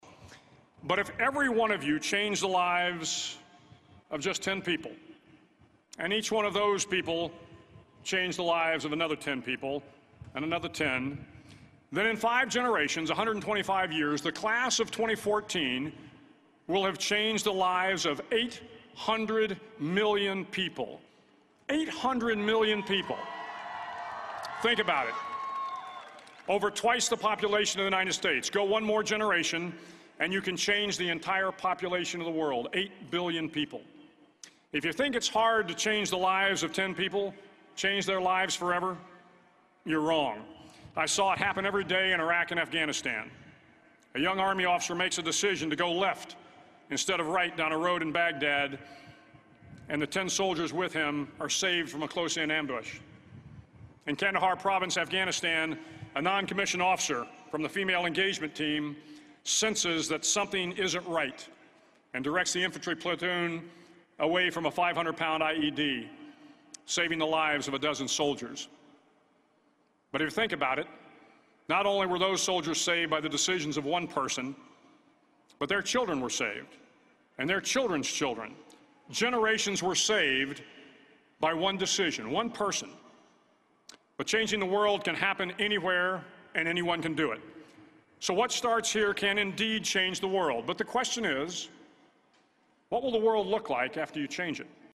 公众人物毕业演讲 第229期:威廉麦克雷文2014德州大学演讲(2) 听力文件下载—在线英语听力室